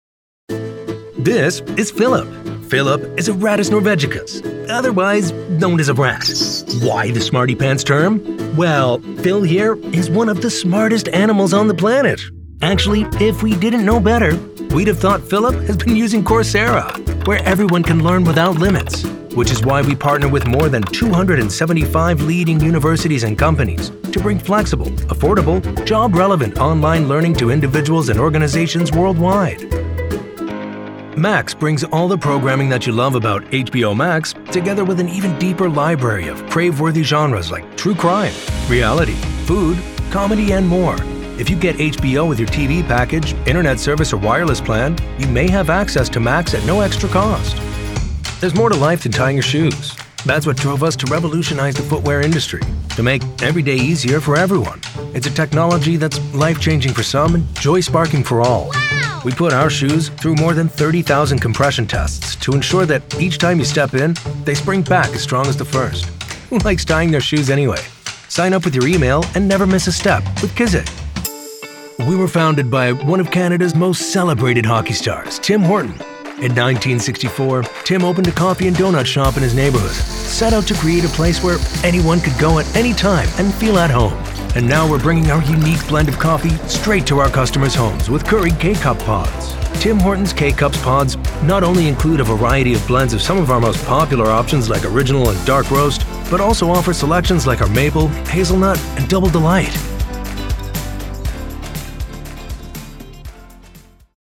Engels (Amerikaans)
Diep, Natuurlijk, Opvallend, Veelzijdig, Warm
Explainer